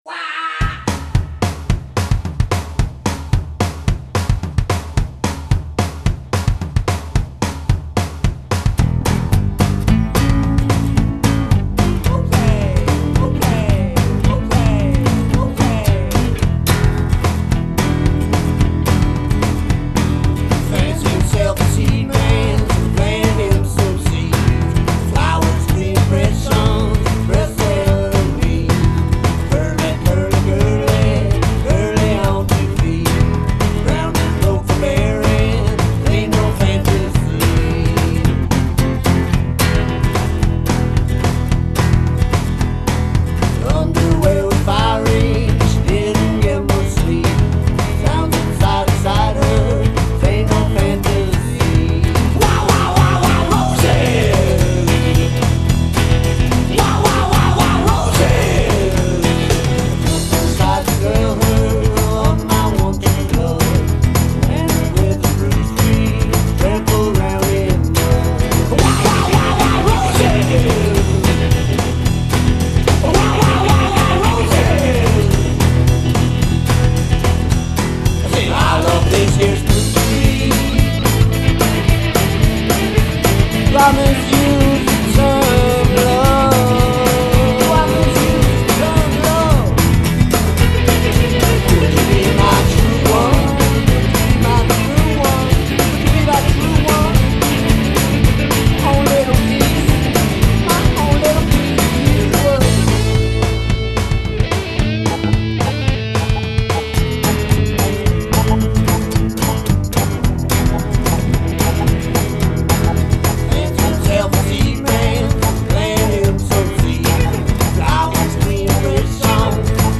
Guitar, Vocals, Drum Programming
Bass, Keyboards, Guitar, Drum Programming
Mandolin